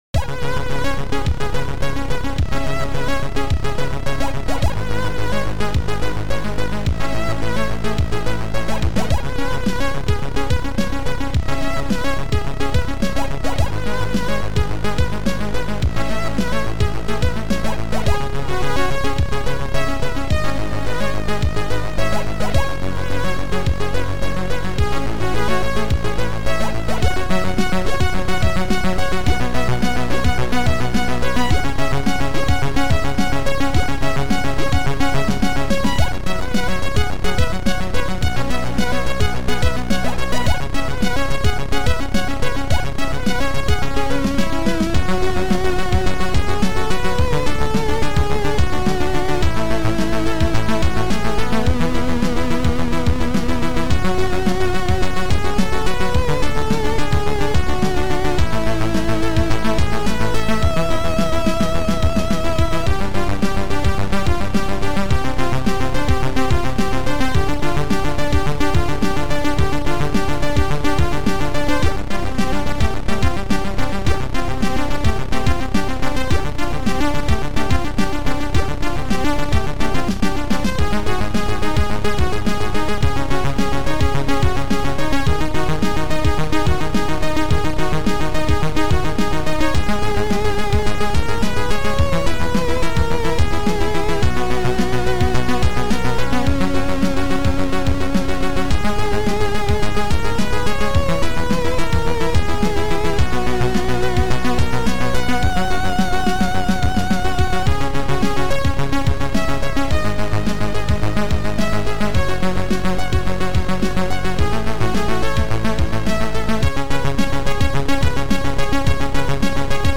Future Composer Module